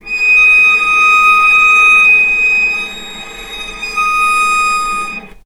healing-soundscapes/Sound Banks/HSS_OP_Pack/Strings/cello/sul-ponticello/vc_sp-D#6-mf.AIF at 01ef1558cb71fd5ac0c09b723e26d76a8e1b755c
vc_sp-D#6-mf.AIF